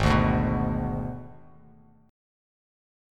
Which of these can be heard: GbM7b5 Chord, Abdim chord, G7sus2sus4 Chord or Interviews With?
Abdim chord